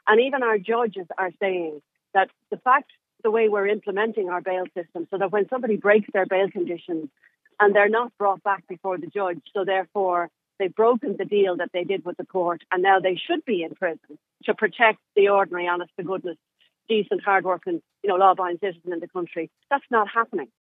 Fine Gael MEP Regina Doherty believes a public inquiry is needed: